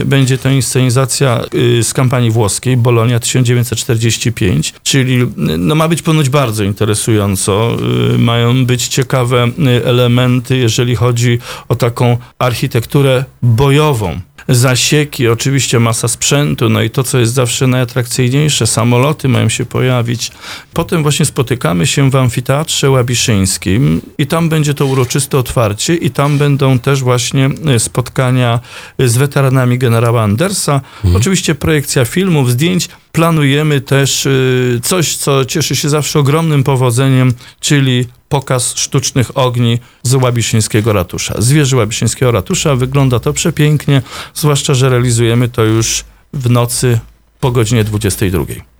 Wczoraj ruszył II Międzynarodowy Festyn Miłośników Kolei Wąskotorowych na Pałukach, dziś rozpoczyna się V jubileuszowa edycja Łabiszyńskich Spotkań z Historią. Poświęcone bohaterowi spod Monte Casino - generałowi Andersowi tegoroczne wydarzenie zainaugurowane zostanie inscenizacją na żwirowni o godzinie 19.00. O tym jakie jeszcze atrakcje czekają pierwszego dnia spotkań,mówi burmistrz Jacek Idzi Kaczmarek.